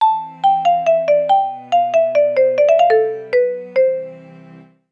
factory_clean.wav